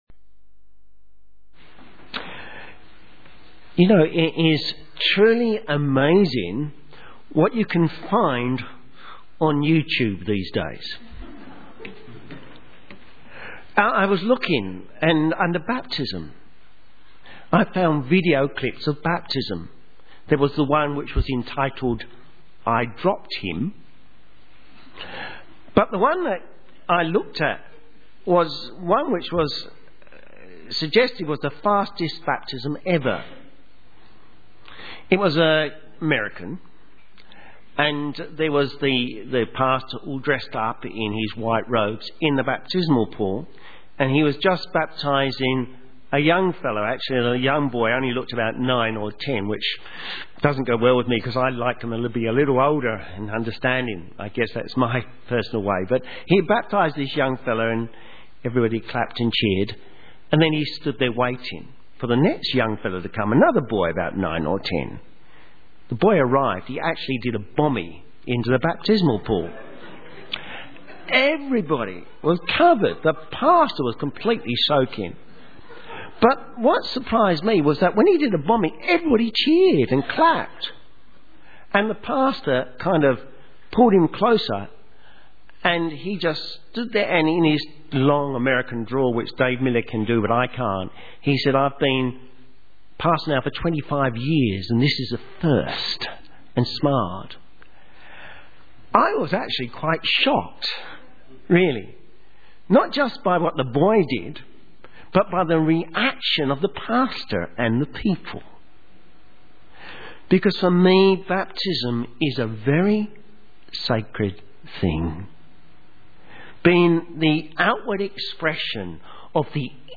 Sermon
Baptism: Eager To Do What Is Good Romans 6:1-14 Synopsis This message was preached at a baptismal service to show that the outcome of Grace is to love the Lord and to keep His commands.